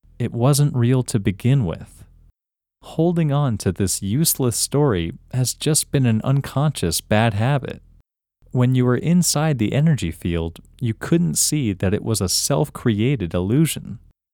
Inner Greatness Global > audio Sections > OUT Male English > OUT – English Male 29